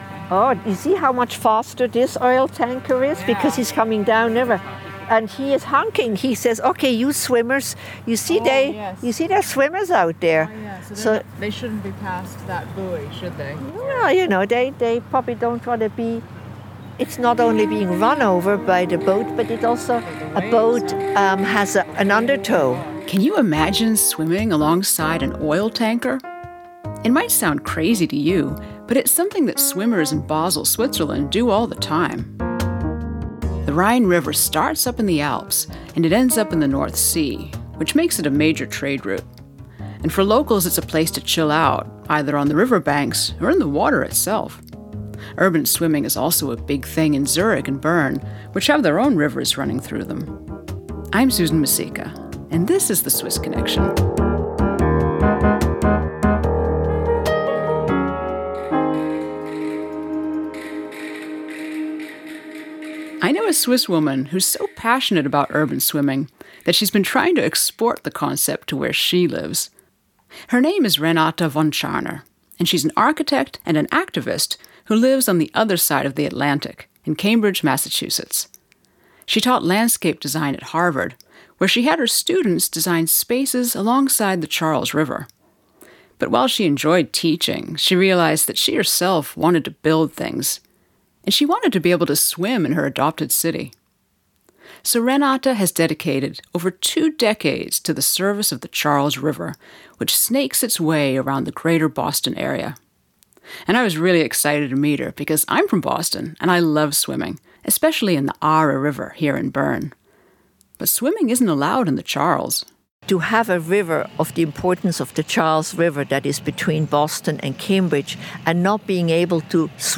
interview next to the Rhine